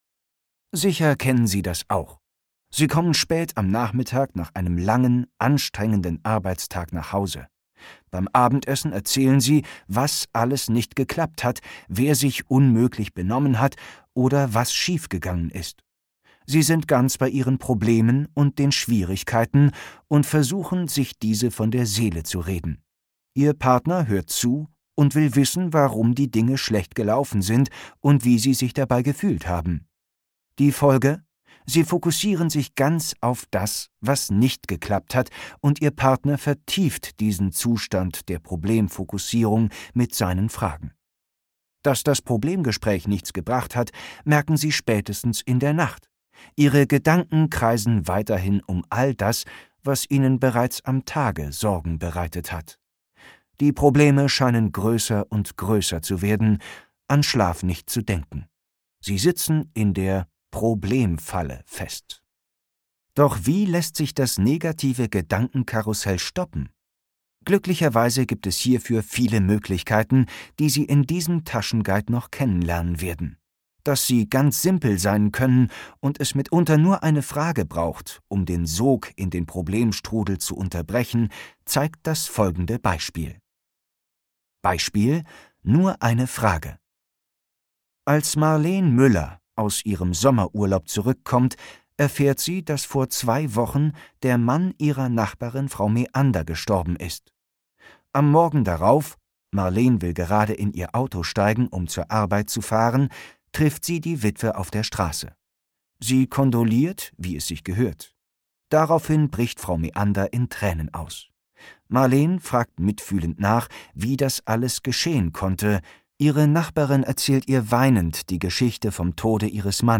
Hörbuchcover von Lösungsorientiert denken und handeln: Wie eine Haltung Ihr Leben verändert